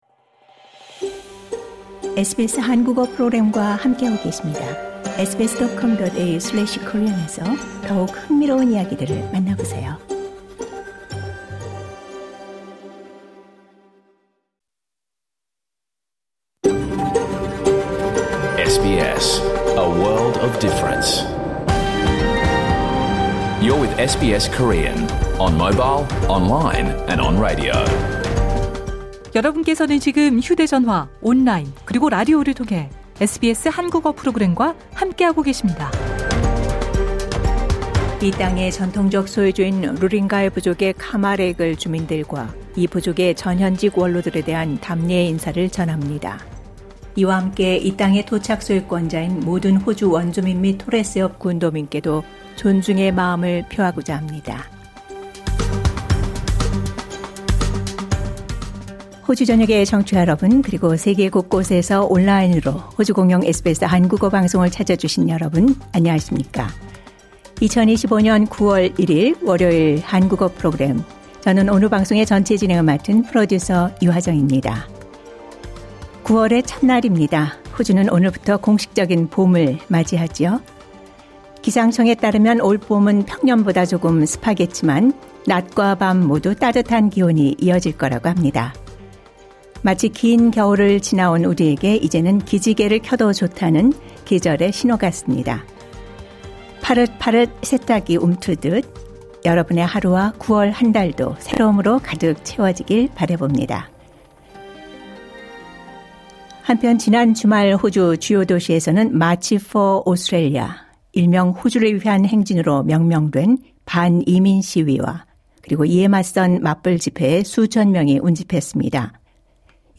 2025년 9월 1일 월요일에 방송된 SBS 한국어 프로그램 전체를 들으실 수 있습니다.